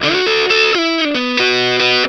BLUESY3 B 90.wav